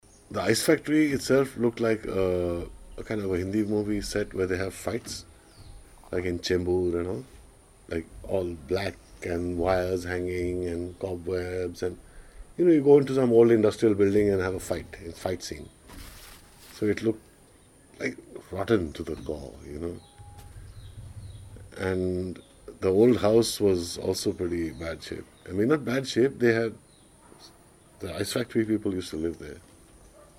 Excerpts from a conversation